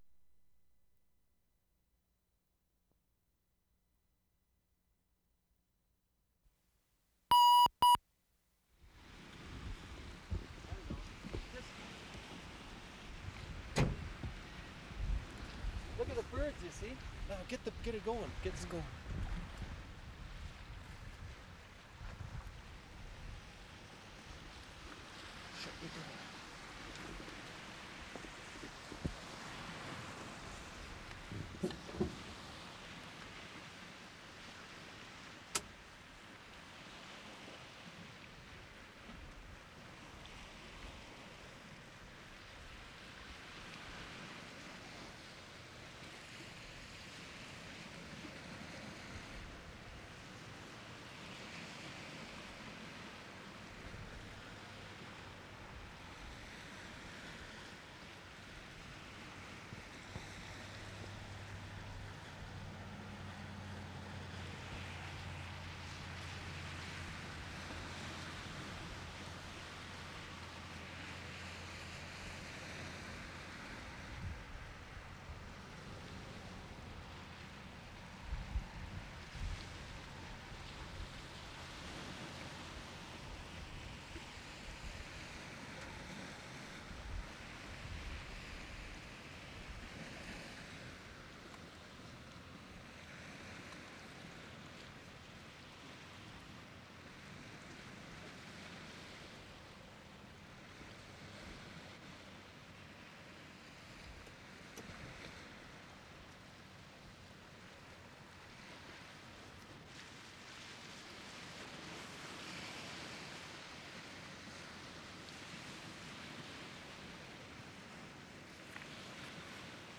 WORLD SOUNDSCAPE PROJECT TAPE LIBRARY
BEACH ON THE WAY TO CAMPBELL RIVER 4'35"
2. Opens on the beach with water sound, surf in middleground. Some wind on microphones.